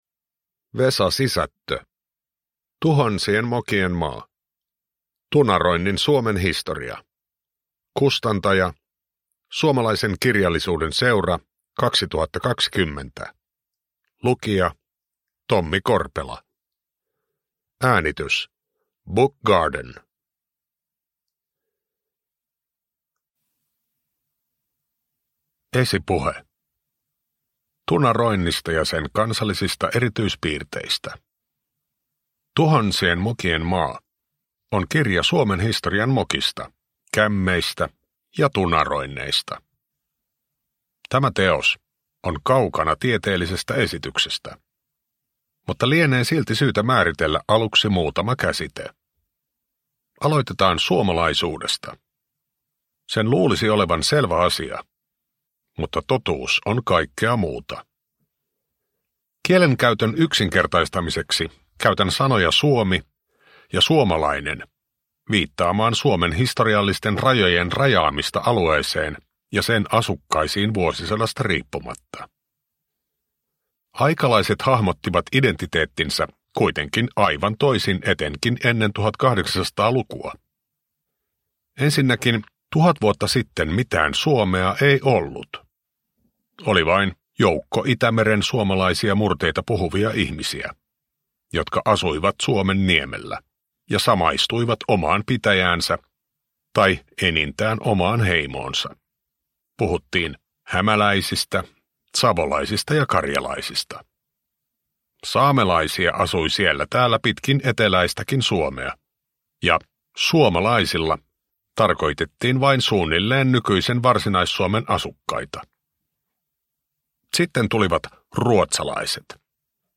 Tuhansien mokien maa – Ljudbok – Laddas ner
Uppläsare: Tommi Korpela